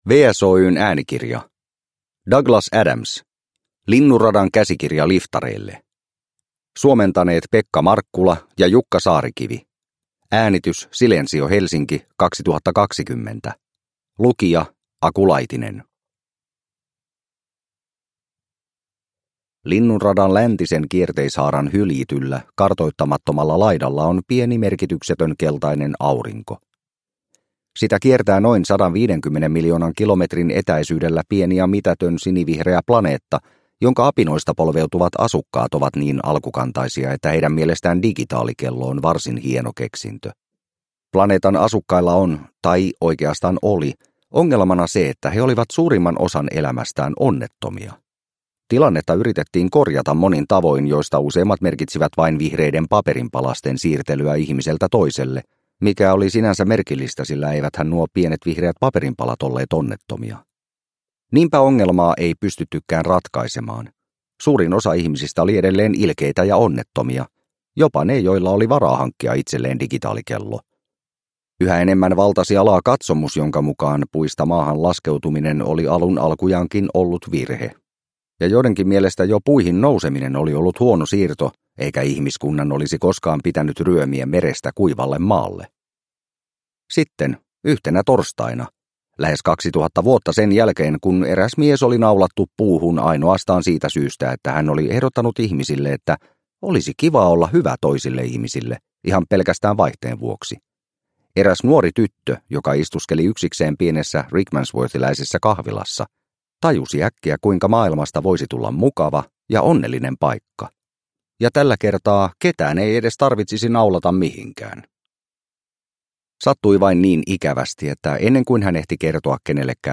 Linnunradan käsikirja liftareille – Ljudbok – Laddas ner